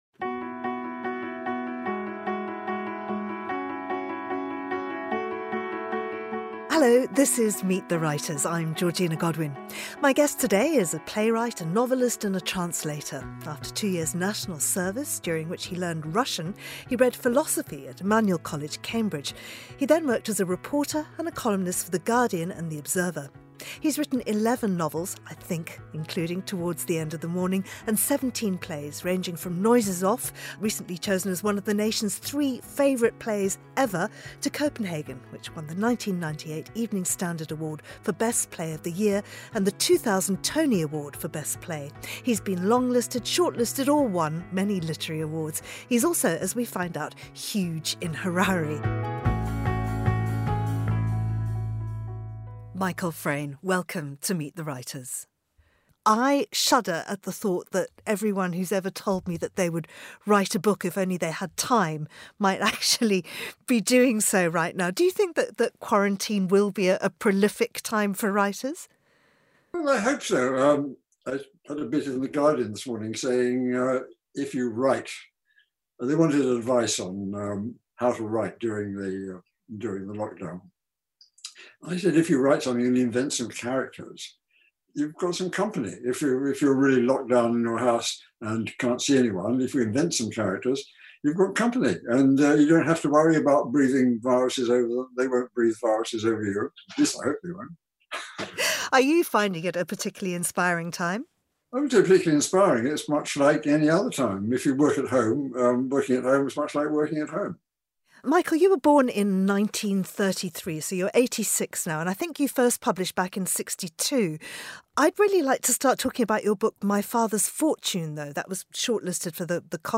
Interviews: American Poet & Writer Cynthia Zarin On Her New Book “Two Cities”
A conversation with the acclaimed poet and New Yorker writer Cynthia Zarin that transports us to two of her favorite cities, Venice and Rome, in a celebration of Italy as the country begins to loosen the longest coronavirus-related lockdown in Europe. The episode features evocative readings from her forthcoming book,Two Cities, which captures the meditative yet constantly surprising nature of travel from a deeply personal point of view.